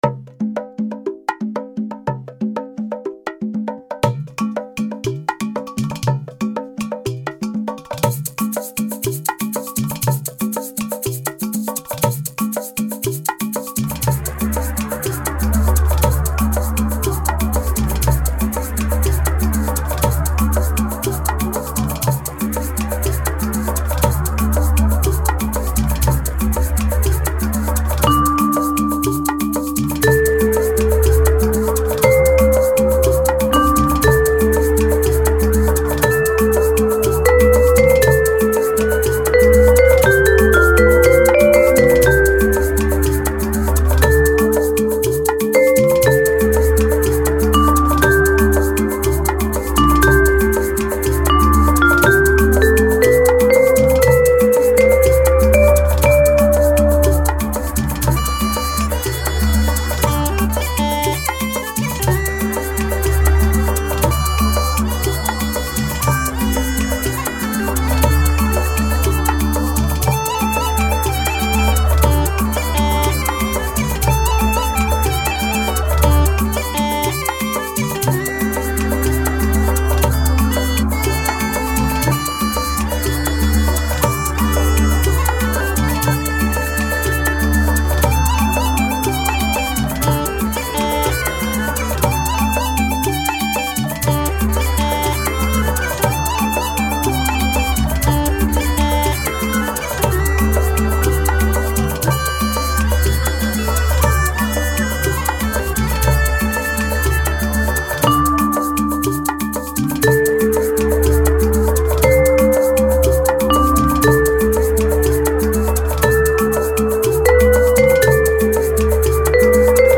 In questa pagina potete ascoltare e scaricare liberamente alcune delle mie composizioni musicali realizzate a computer con l’aiuto del mio Mac e di una tastiera usb.
I suoni riflettono i generi da me preferiti, in particolare jazz, blues e world music per cui non saprei bene come inquadrare la mia musica, forse l’etichetta New Age potrebbe essere appropriata.....